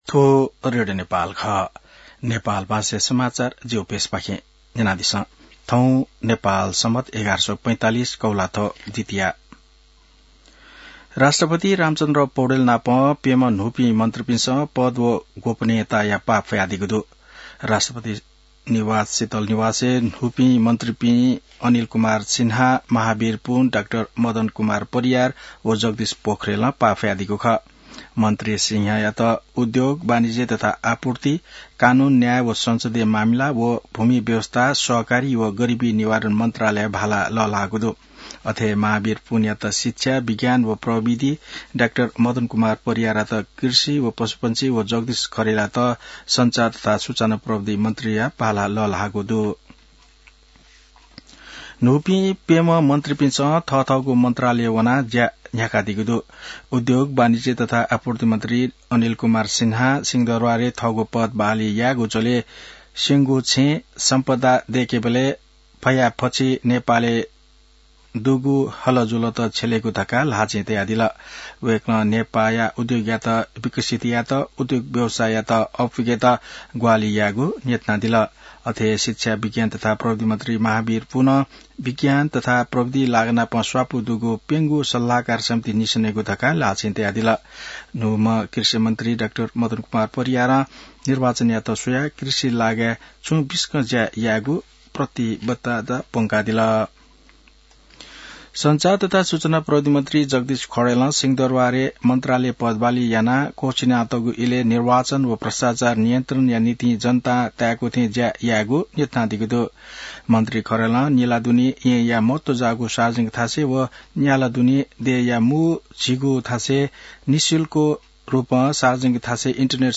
नेपाल भाषामा समाचार : ७ असोज , २०८२